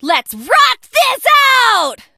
janet_start_vo_05.ogg